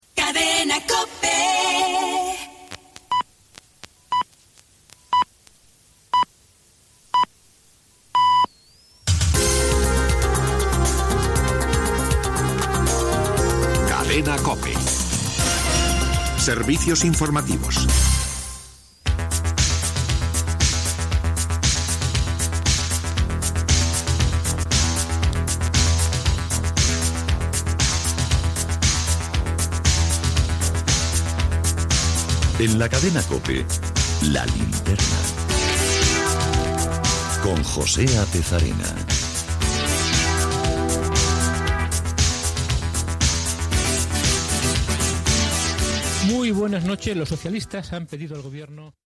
Indicatiu de la cadena, horàries, indicatiu dels serveis informatius, careta del programa.
Informatiu